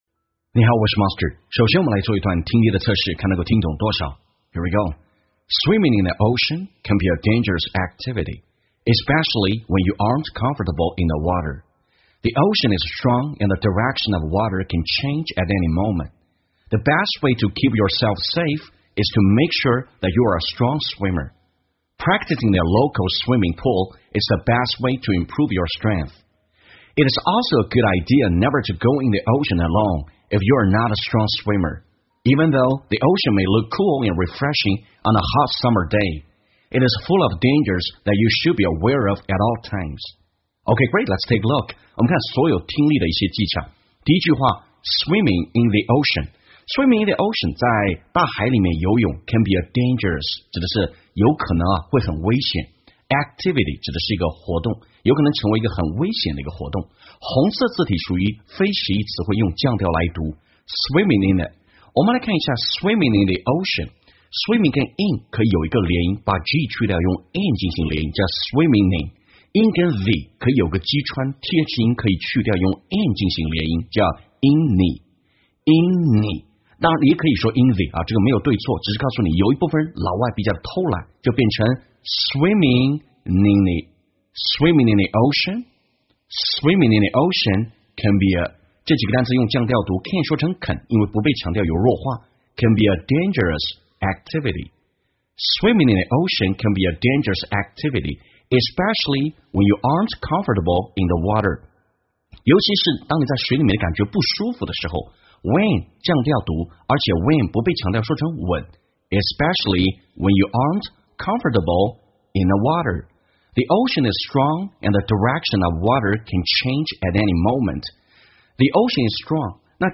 在线英语听力室瞬间秒杀听力 第518期:海中游泳须知的听力文件下载,栏目通过对几个小短句的断句停顿、语音语调连读分析，帮你掌握地道英语的发音特点，让你的朗读更流畅自然。